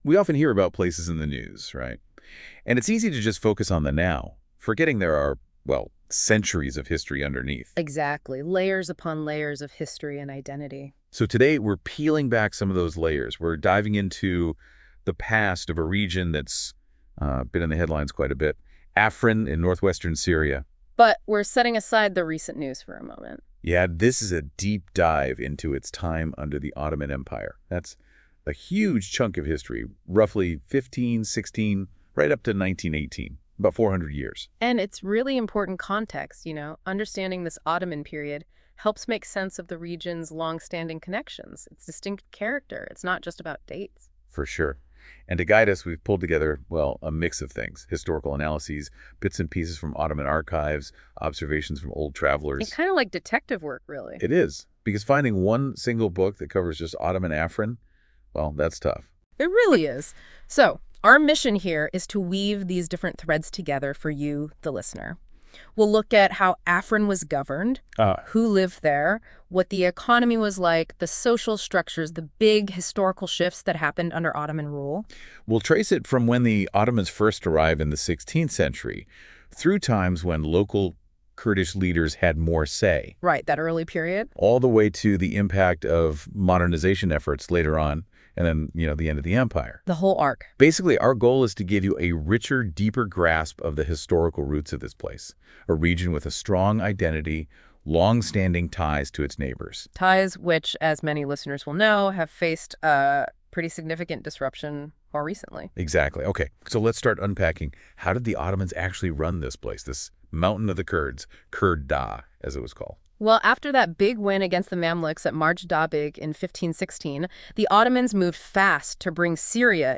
Note: This was made with AI research and AI audio output, and does not conform to academic standards.